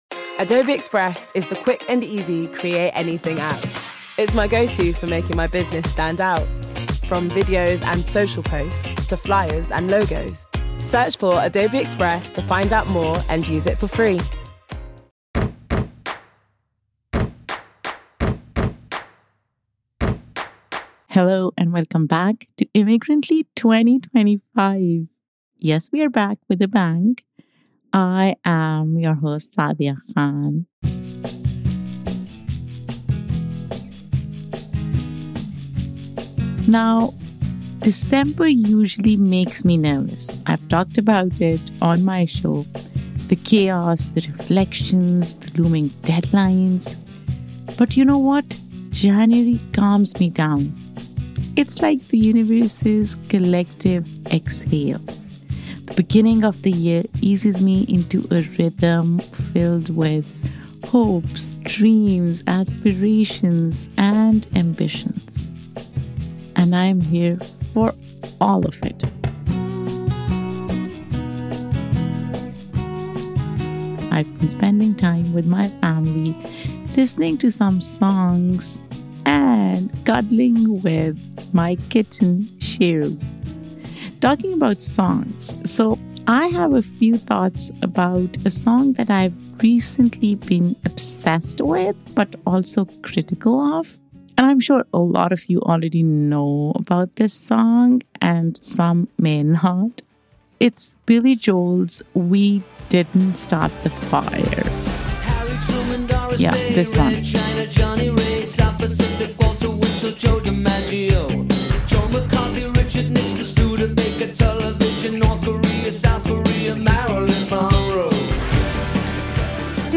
It’s an unfiltered, inspiring conversation that sets the tone for 2025 Immigrantly is a weekly podcast that celebrates the extraordinariness of immigrant life.